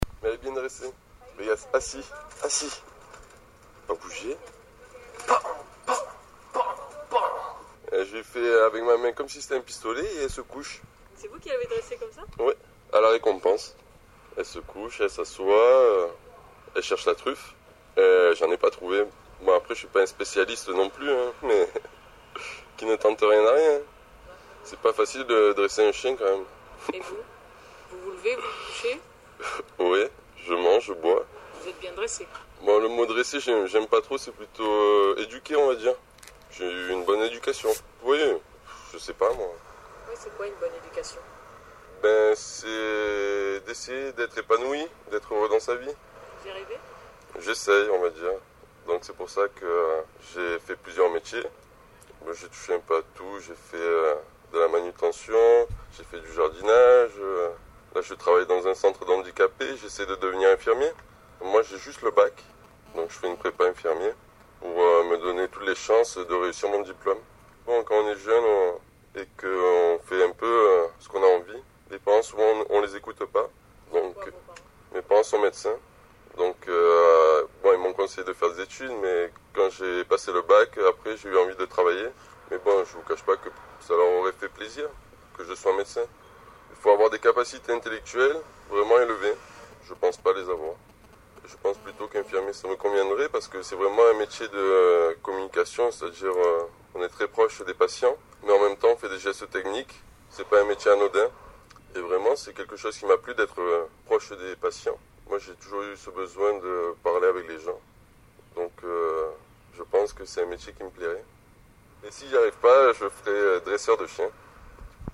C’était ici à la radio un matin.